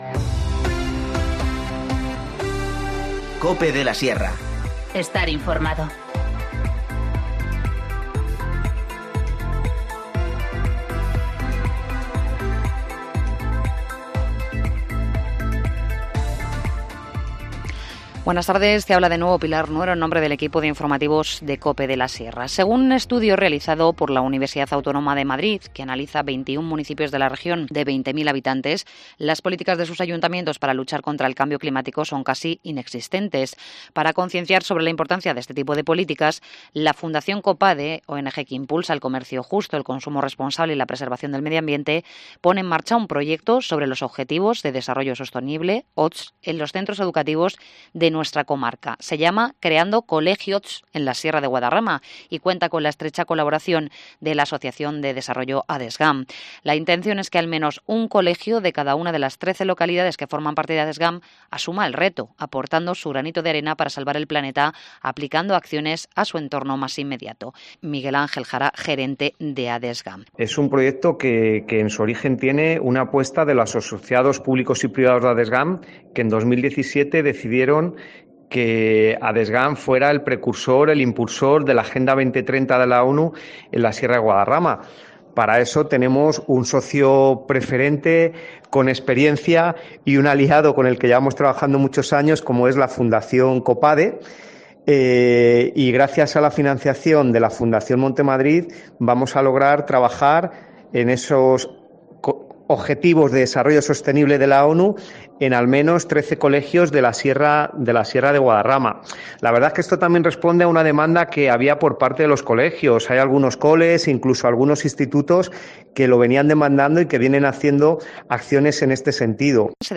Informativo Mediodía 12 diciembre 14:50h
INFORMACIÓN LOCAL